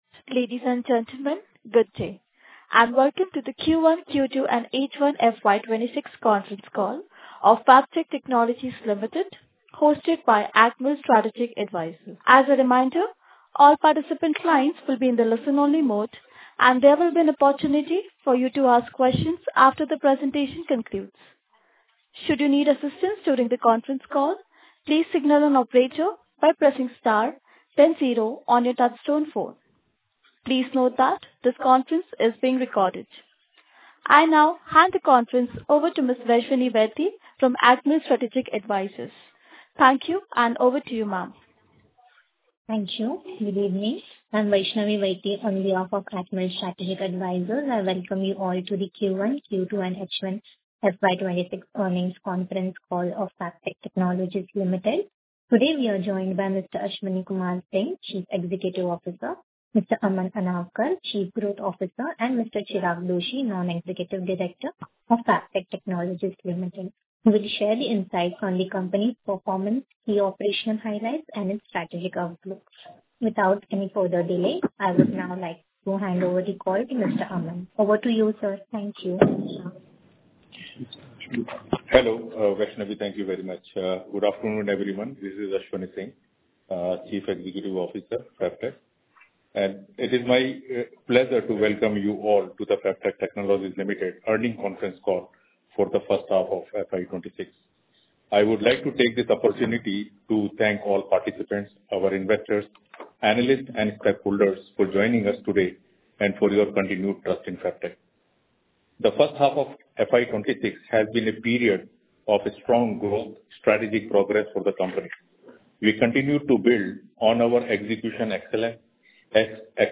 Audio Recording of post earning call